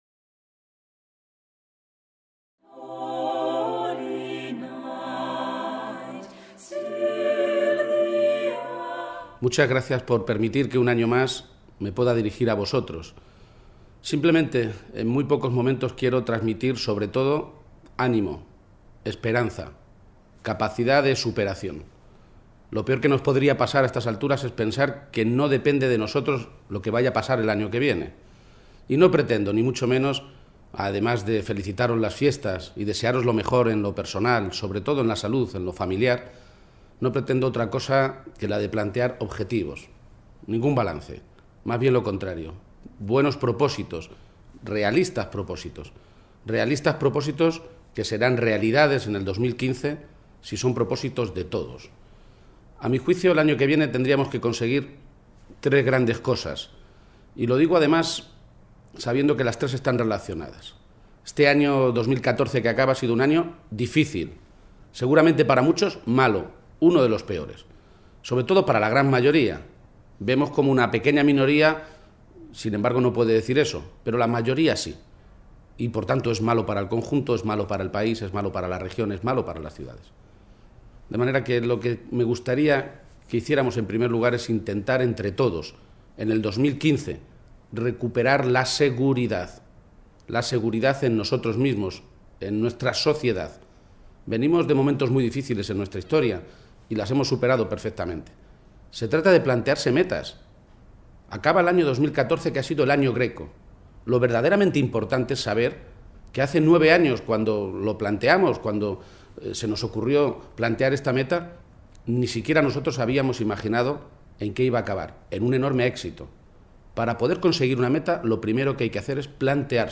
Debemos recuperar la igualdad y la solidaridad afirma Emiliano García-Page, alcalde de Toledo en su mensaje de Navidad
mensaje_navidad_alcalde.mp3